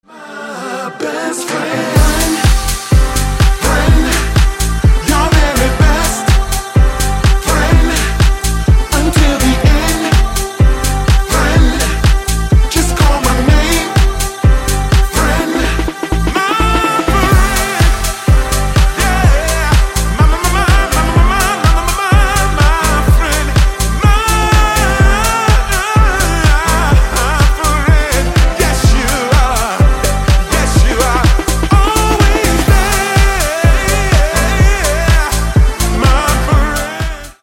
Танцевальные Рингтоны